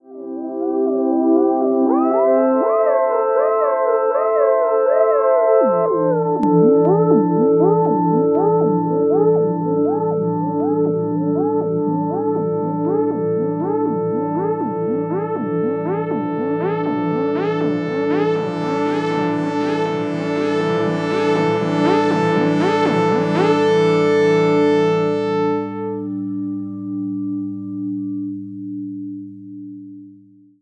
10 - Portamento Synth
10_Portamento_Synth.wav